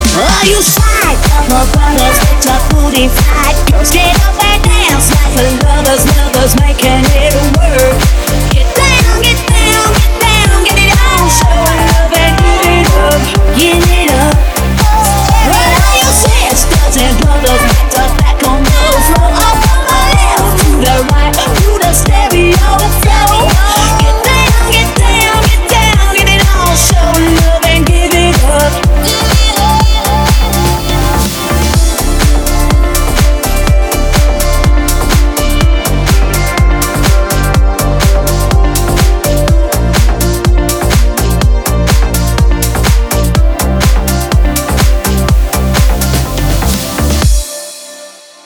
ритмичные
громкие
remix
женский голос
Electronic
Club House
энергичные
Стиль: club house